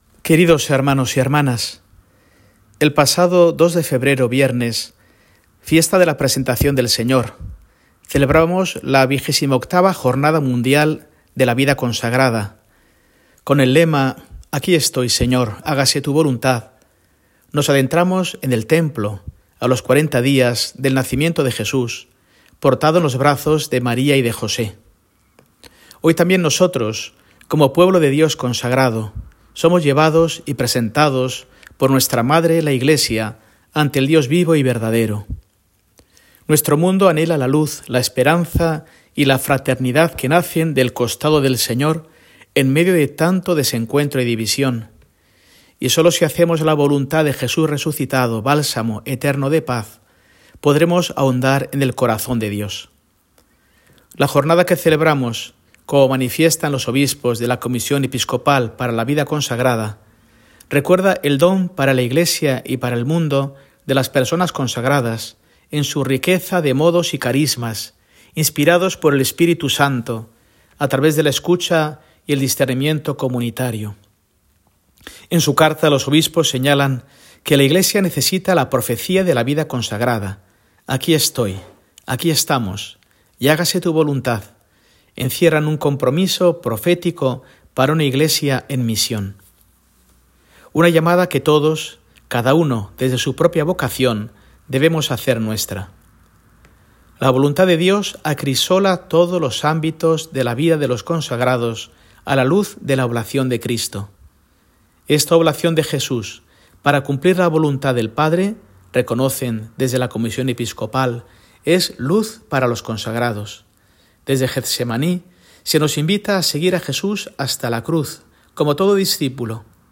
Escucha aquí el mensaje de Mons. Mario Iceta para este domingo